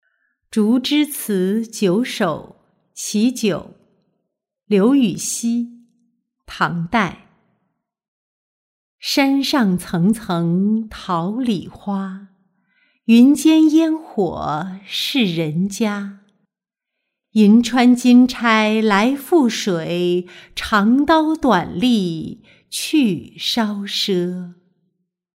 竹枝词九首·其九-音频朗读